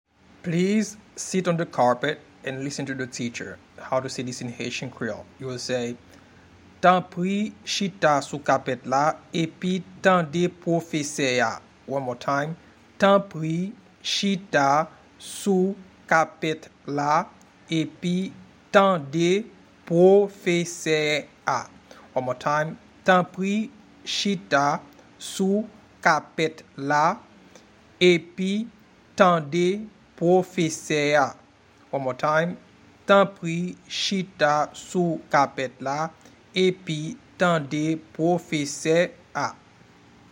Pronunciation and Transcript: